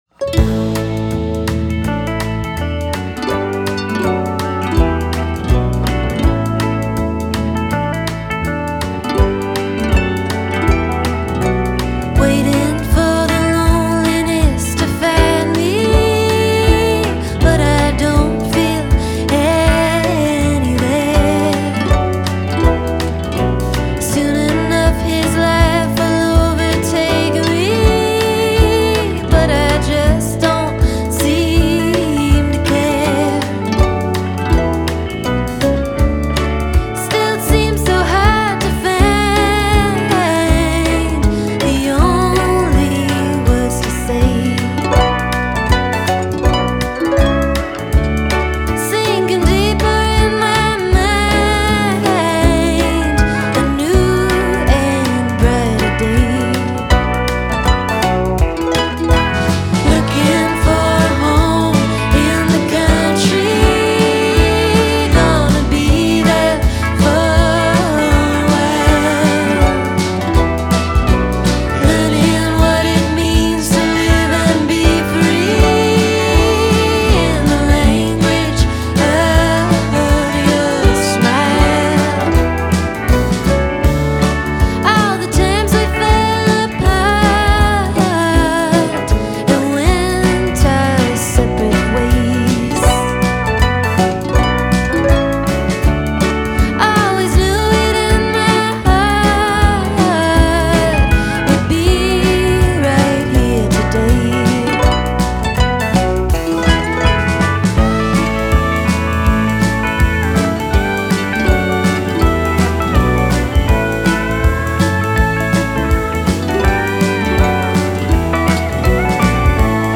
Klingt durch die klaren Cymbals richtig schön frisch.